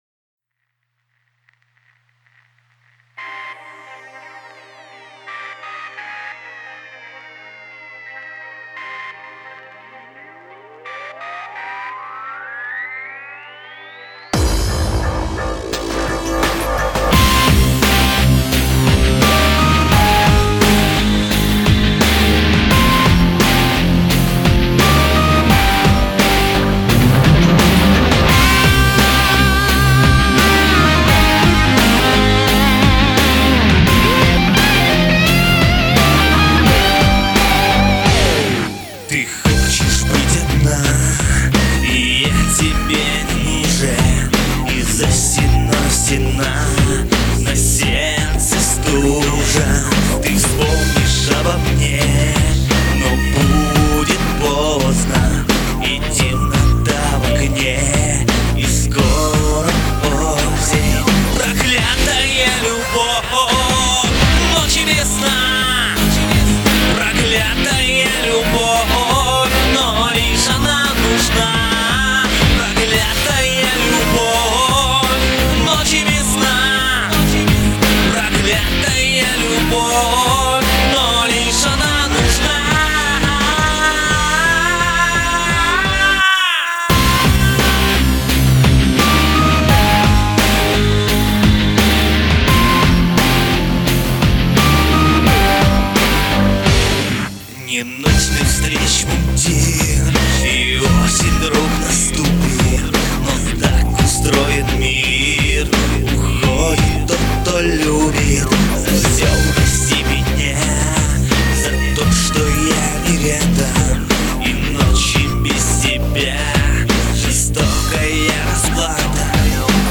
Запись- лучший образчик записи и эффектов!!!!)))